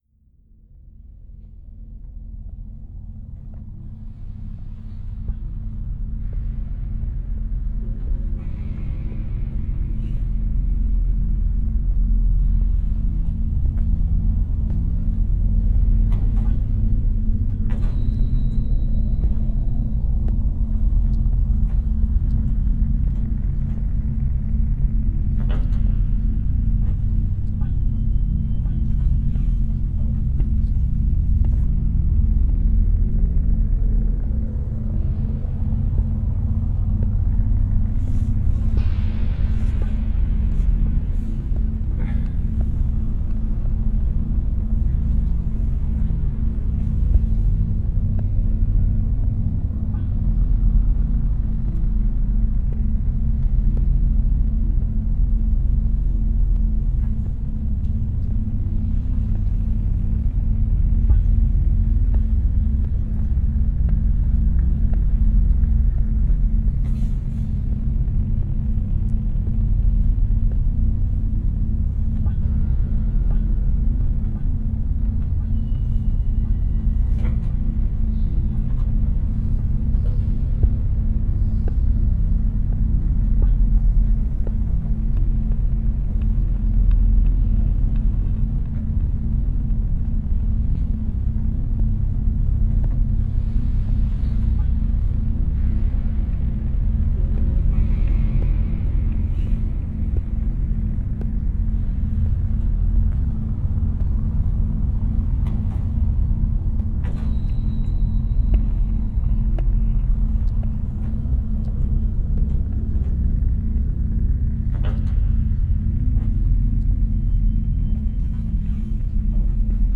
Brainwave entrainment for deep, deep practice.